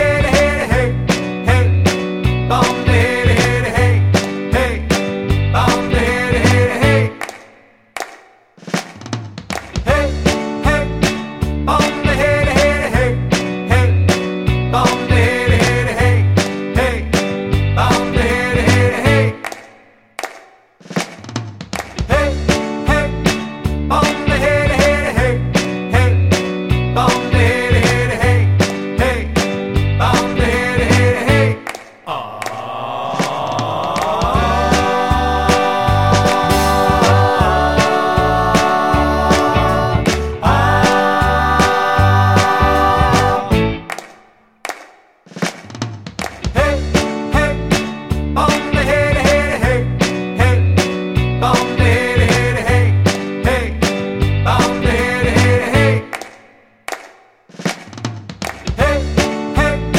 No Saxophone Rock 'n' Roll 2:44 Buy £1.50